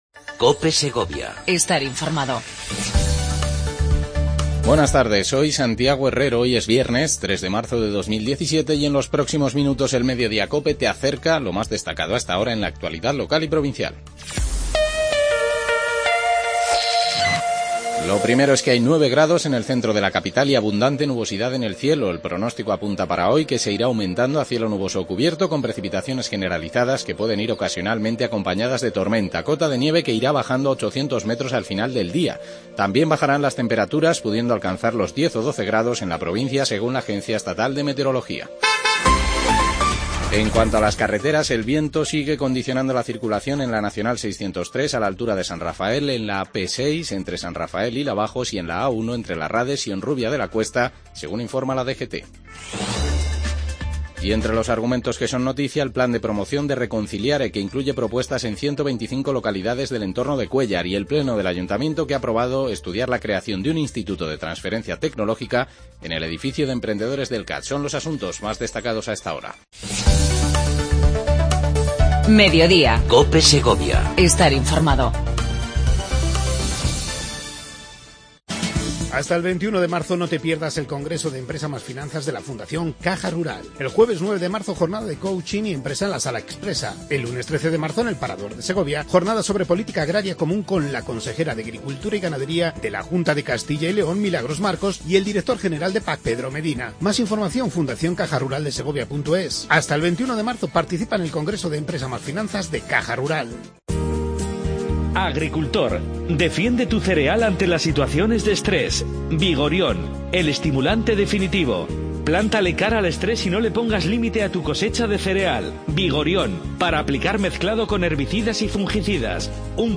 Avance de las noticias más destacadas del día. Entrevista con Daniel López, Alcalde de Fuentepelayo sobre la Feria del Angel.